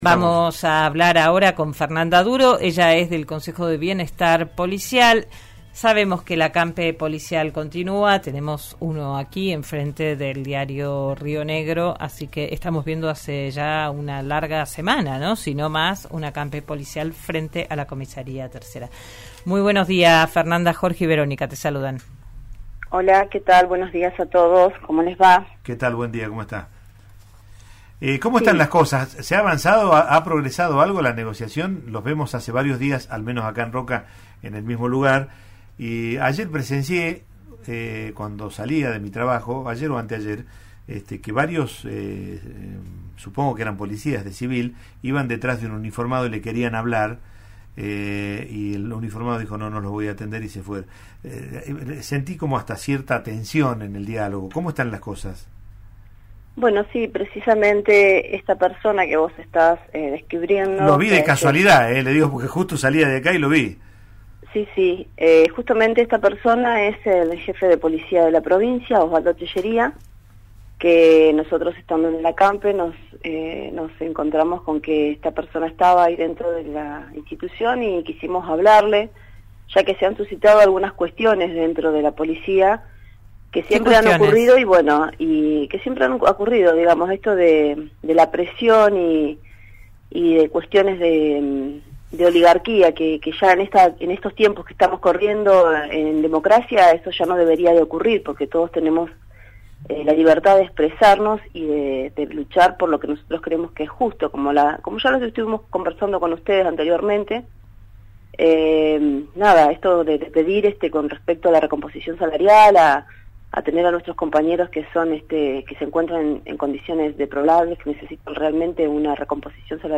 en diálogo con «Digan lo que digan» en RN RADIO: